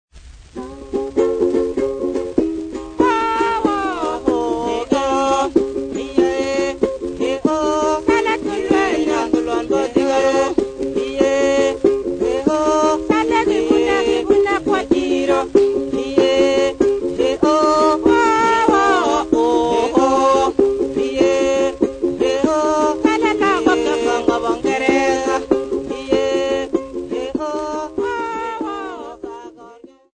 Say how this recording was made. Field recordings Africa Kenya Kericho f-sa